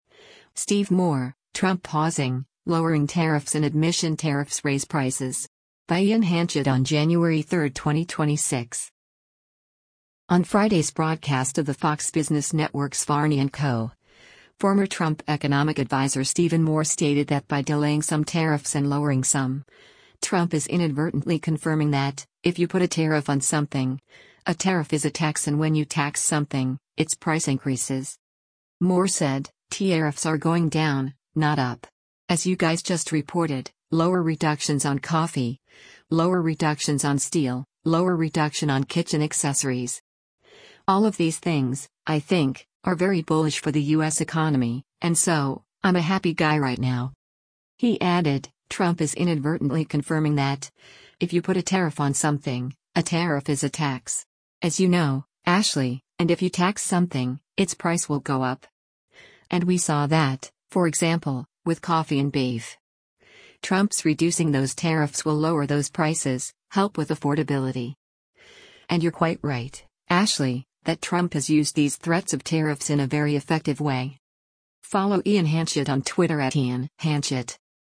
On Friday’s broadcast of the Fox Business Network’s “Varney & Co.,” former Trump Economic Adviser Stephen Moore stated that by delaying some tariffs and lowering some, “Trump is inadvertently confirming that, if you put a tariff on something, a tariff is a tax” and when you tax something, its price increases.